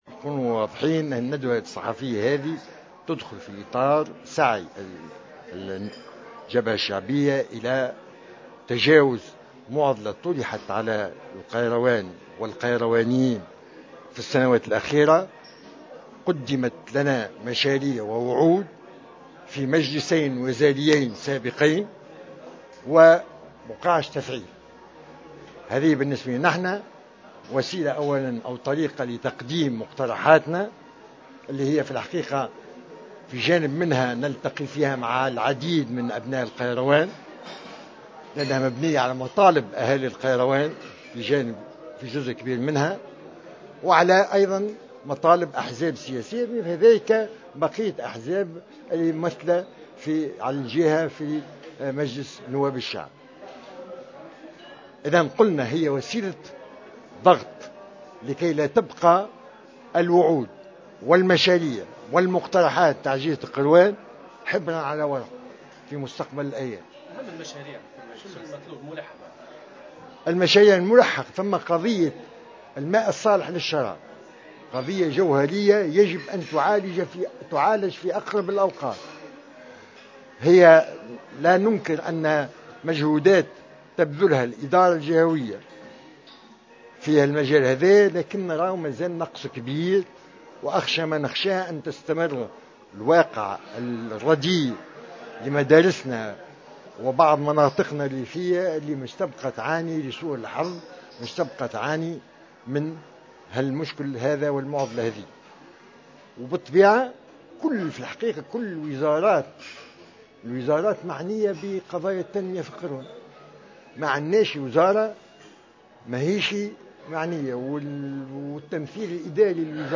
وأوضح في تصريح لـ"جوهرة أف أم" على هامش ندوة صحفية، أن ولاية القيروان تعاني من انعدام مشاريع تنموية ومرافق خدمية لصالح المواطنين في القيروان على غرار الماء الصالح للشراب الذي اعتبره قضية ملحة.